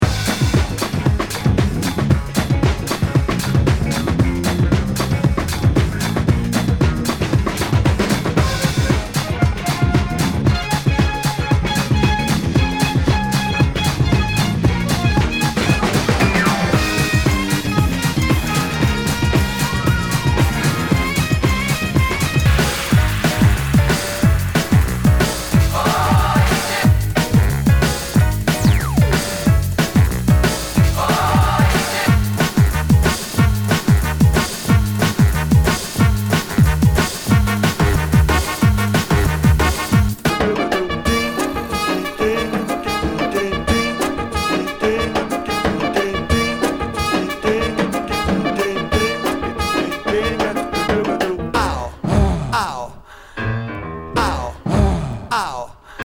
VG Nu- Jazz/BREAK BEATS ナイス！
(Samba)